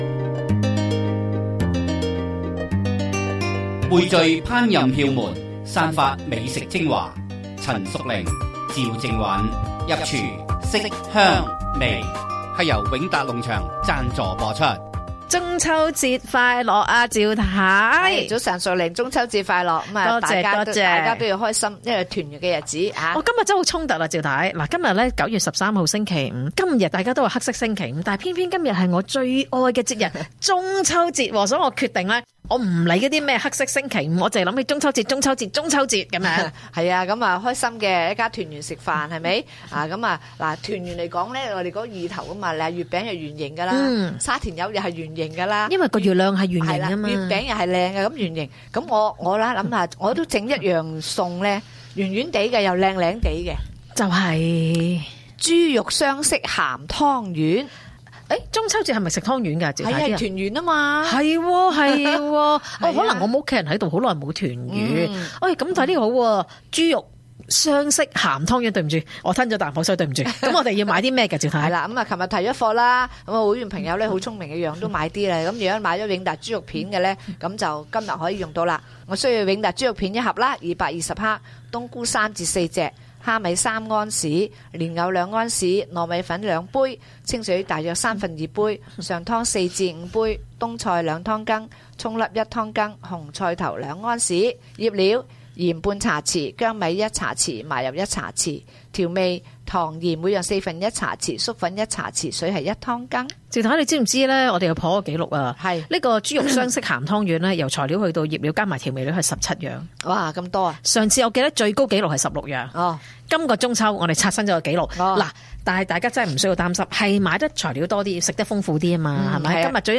stay tuned for Wingtat’s sponsored AM1320 Radio Program "Kitchen Talk"